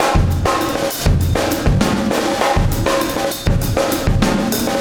Extra Terrestrial Beat 15.wav